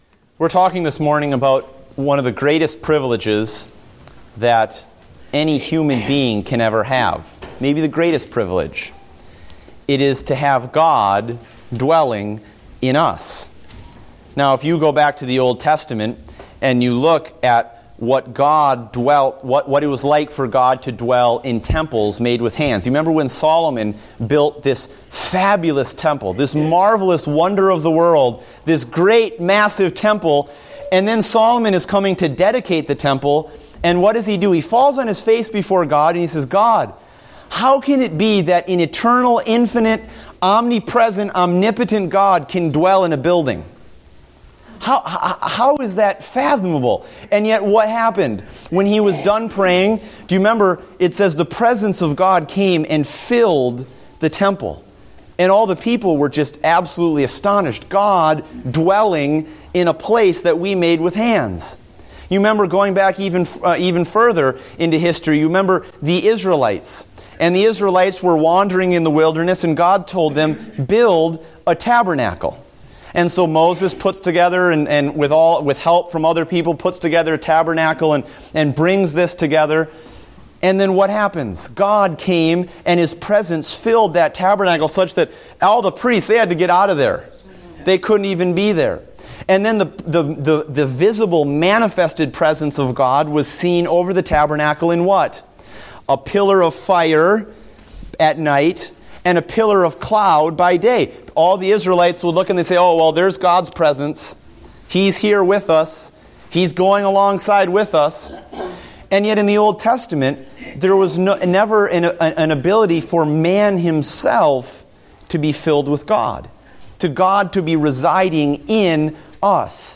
Date: November 23, 2014 (Adult Sunday School)